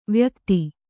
♪ vyakti